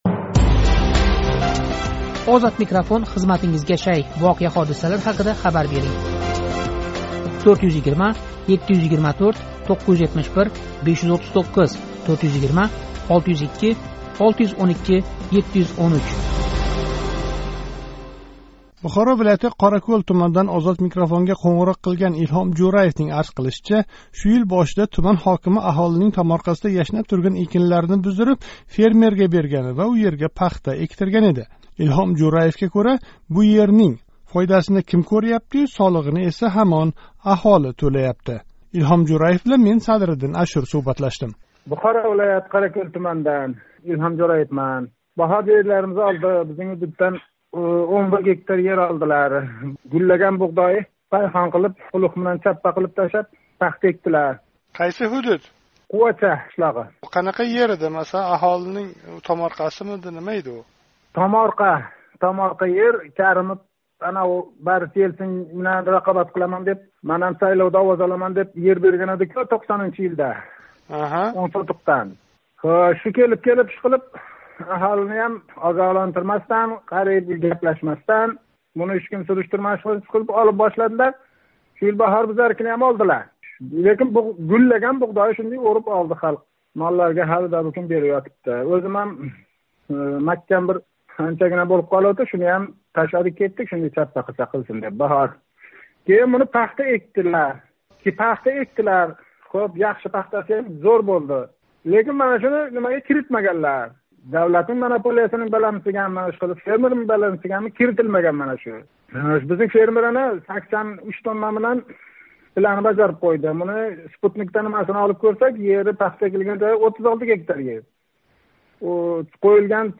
Бухоро вилояти Қоракўл туманидан OzodMikrofonга қўнғироқ қилган